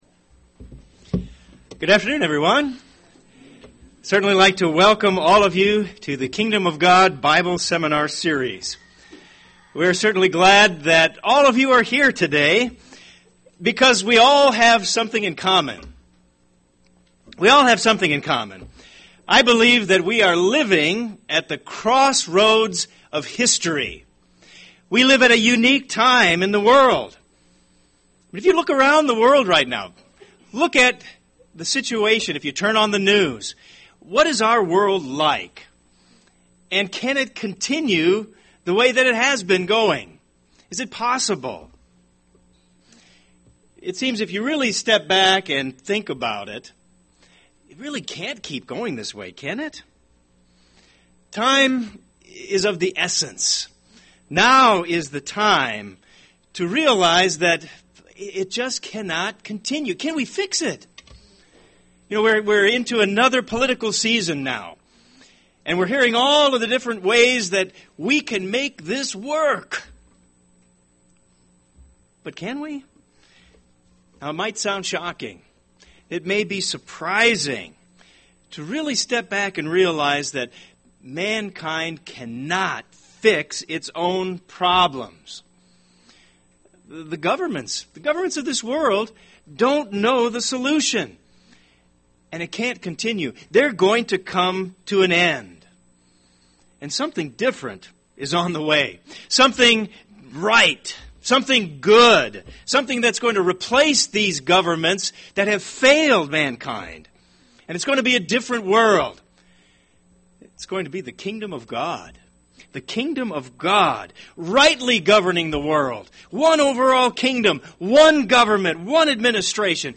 This seminar is a welcoming introduction from Jesus Christ to God’s Kingdom, showing why it is a missing dimension of knowledge in the world's governments, business, education, religious systems - and especially why the Kingdom of God is a missing dimension in the understanding of modern Christianity.